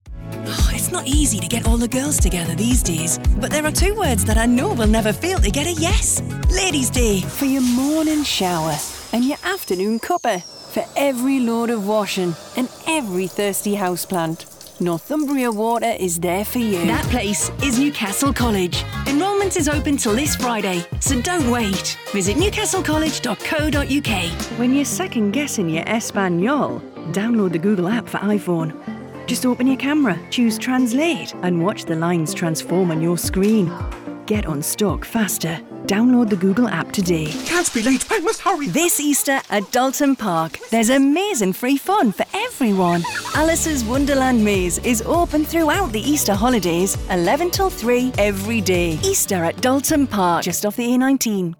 Female
Bright, Confident, Corporate, Engaging, Friendly, Natural, Reassuring, Warm, Witty, Versatile
Northern (native), Geordie (native), Neutral British (native) RP, Scottish, Liverpudlian, Southern.
Microphone: Neumann TLM103, Sennheiser 416,